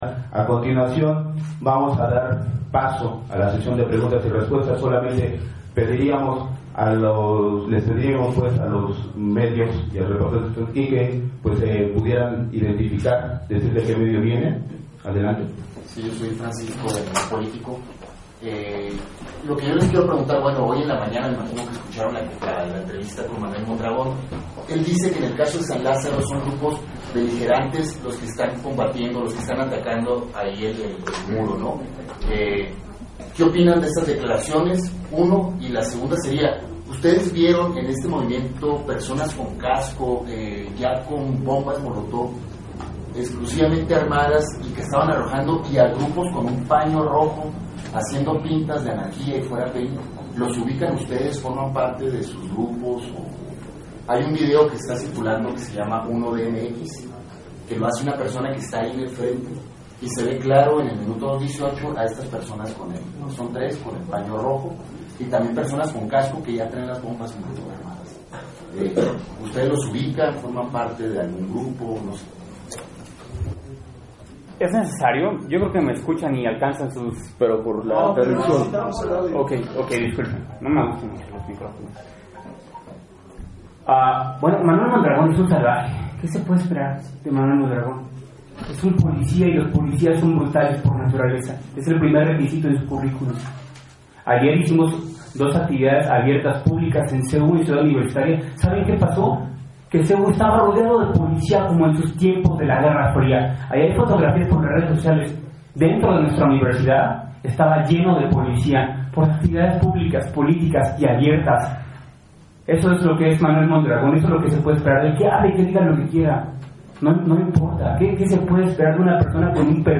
Conferencia de Prensa de Grupos Anarquistas y Marcha
El pasado 7 de diciembre se realizó una rueda de prensa en las instalaciones de Centro Nacional de Comunicación Social (Cencos), en la cual los compañeros de la Cruz Negra Anarquista y la Alianza Anarquista Revolucionaria dieron su posición política con respecto a los sucesos del primero de diciembre.
Conferencia_cna_alianza_anarquista_preguntas.mp3